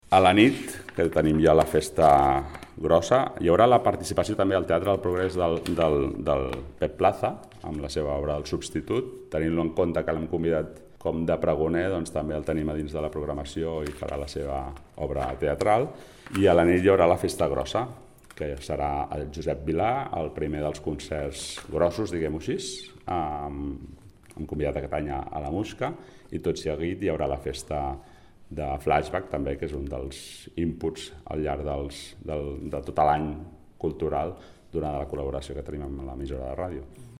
Sergi Corral, regidor de Cultura